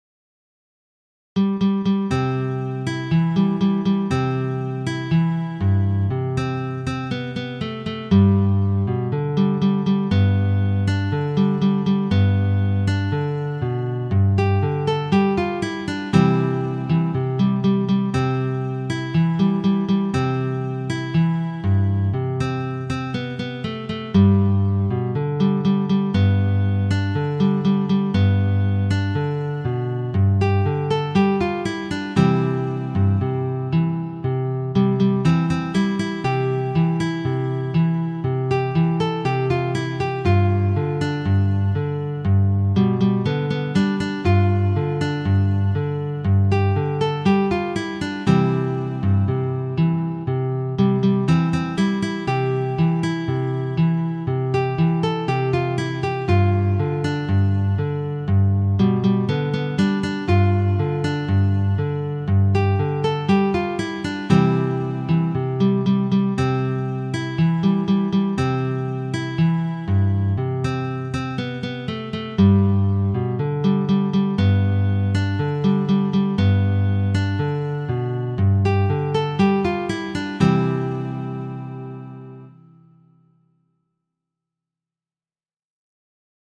Gitarre, akustische Gitarre